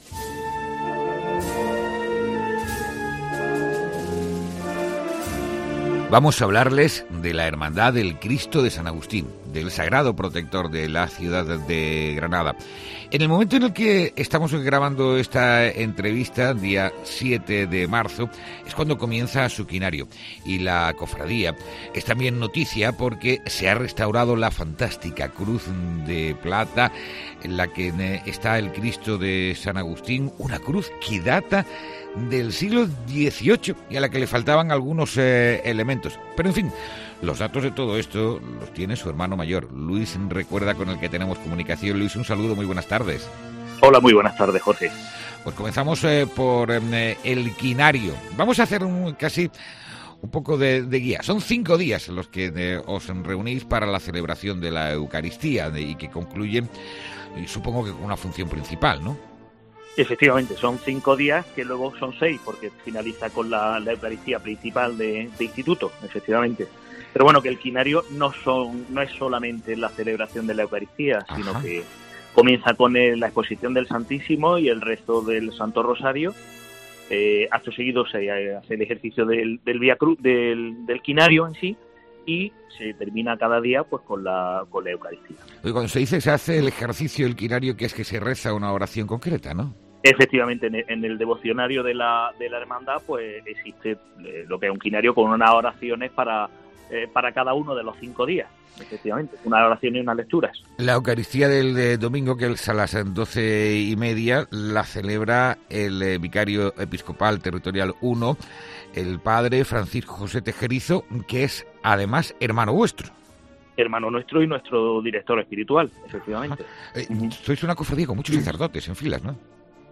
ENTREVISTA|| La Cofradía de San Agustín repone las piezas de la cruz del Cristo que fueron robadas